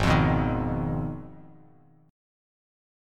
G#m6 chord